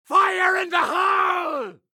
fire in the hole geometry dash - Bouton d'effet sonore